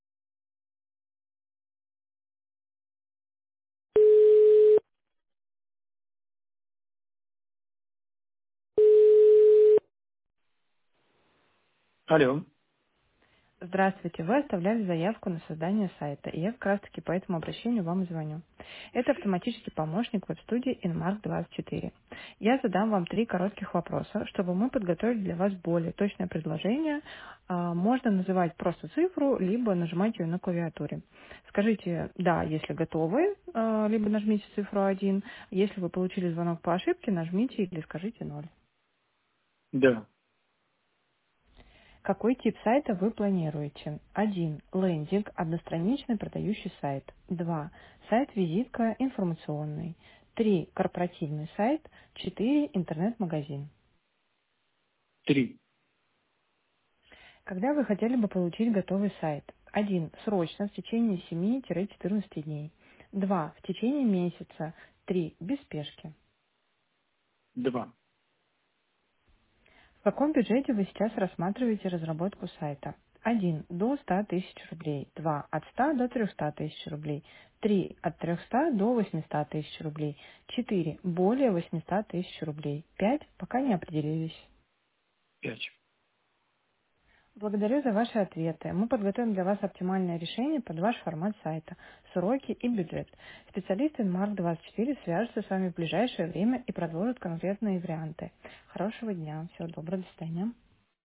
Текст озвучивается живым человеческим голосом, что вызывает доверие.